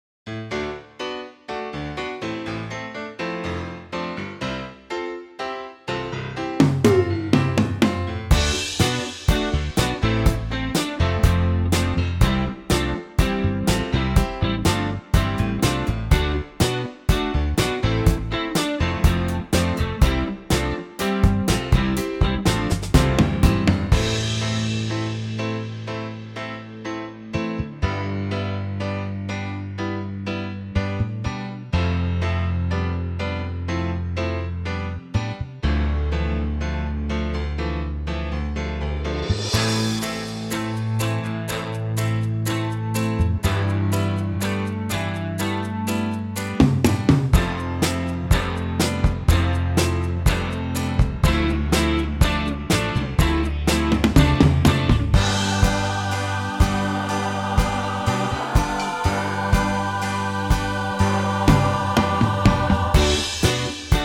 Unique Backing Tracks
key - C - vocal range - A to A